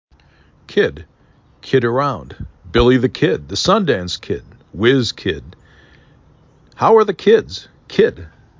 3 Letters, 1 Syllable
3 Phonemes
k i d